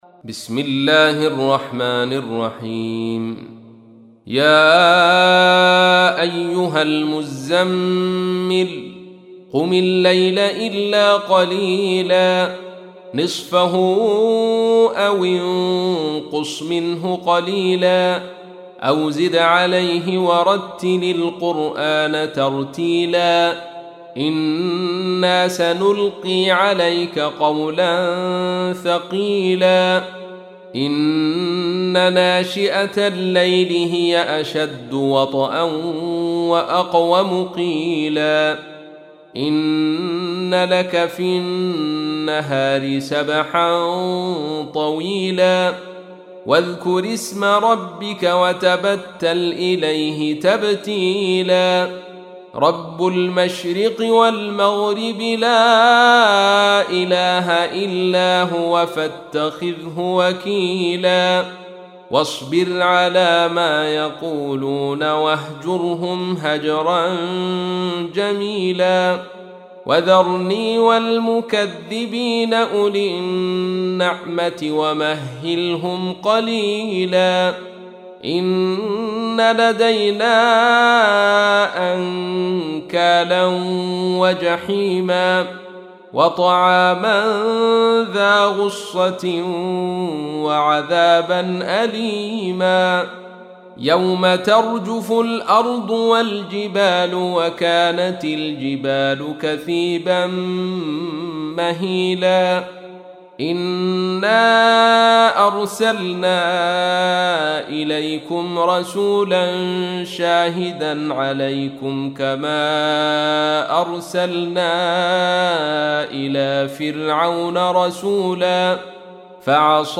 تحميل : 73. سورة المزمل / القارئ عبد الرشيد صوفي / القرآن الكريم / موقع يا حسين